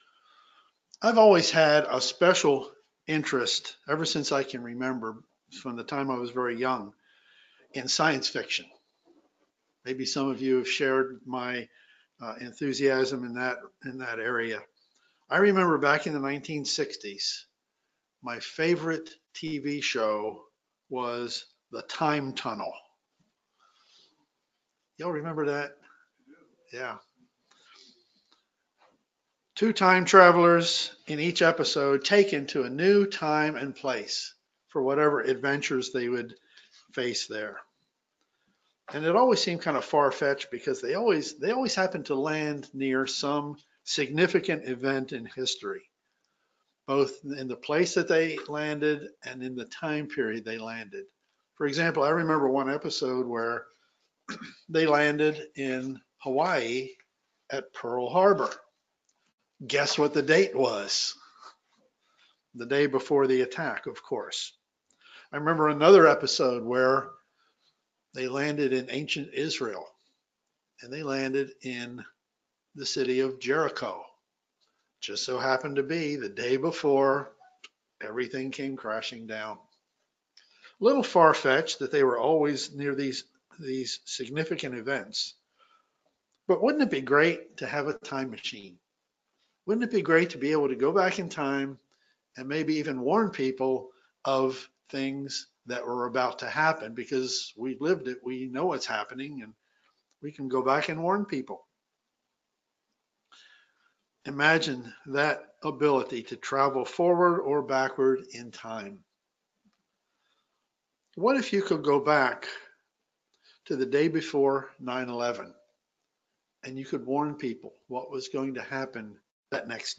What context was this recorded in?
Given in Philadelphia, PA